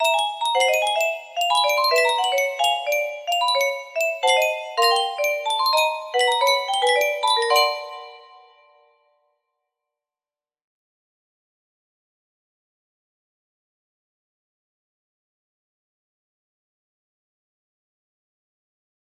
zim music box melody